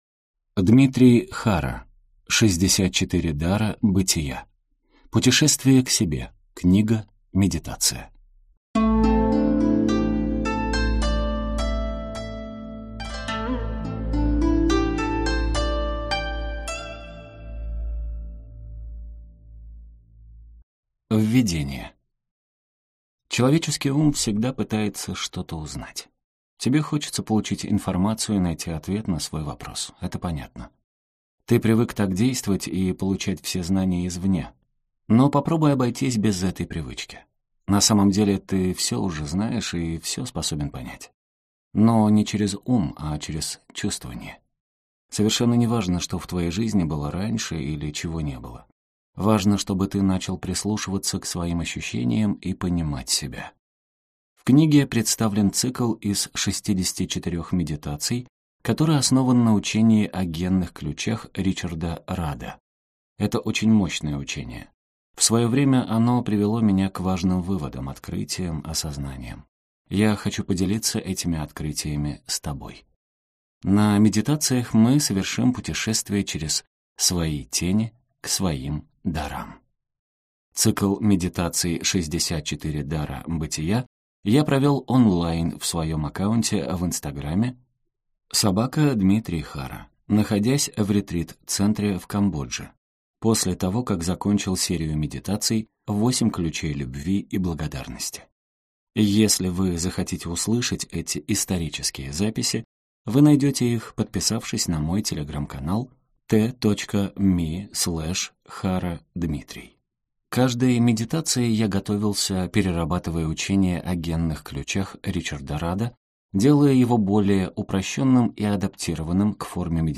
Аудиокнига 64 дара бытия. Путешествие к себе. Книга-медитация | Библиотека аудиокниг